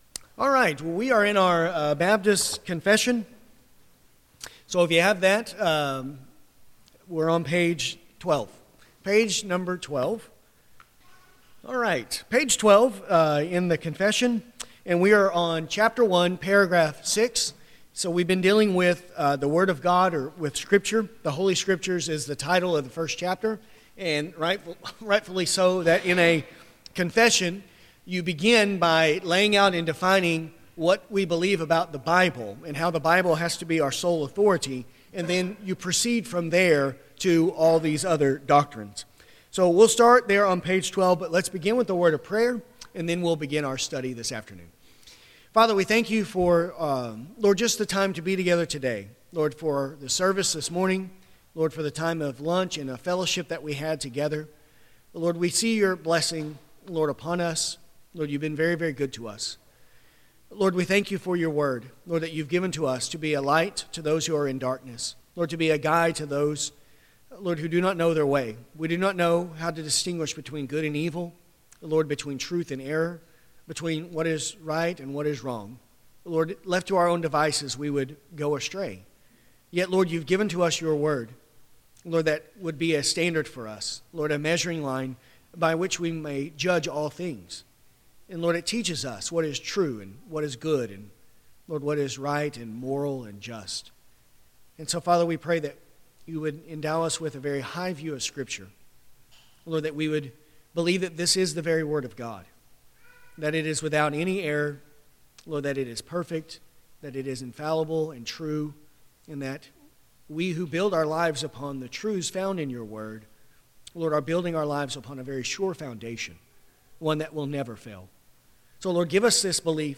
This lesson covers paragraphs 1.6 – 1.7. To follow along while listening, use the link below to view a copy of the confession.